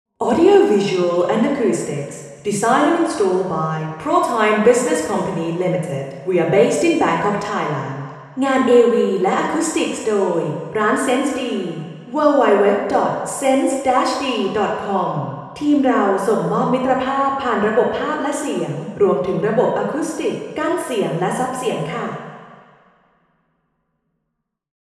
Room: Vethes Samosorn, Ministry of Foreign Affairs
Test Position 1: 4 m